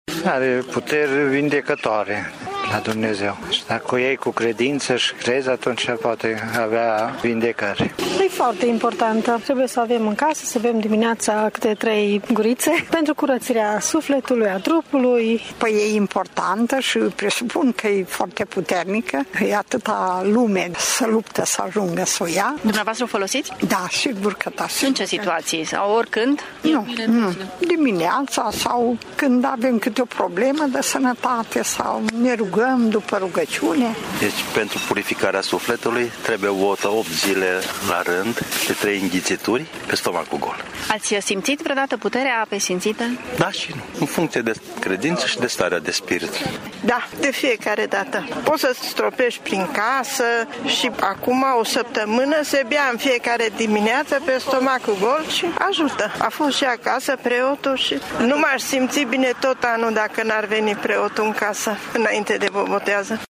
Și mulți dintre oameni cred în puterile acestei ape: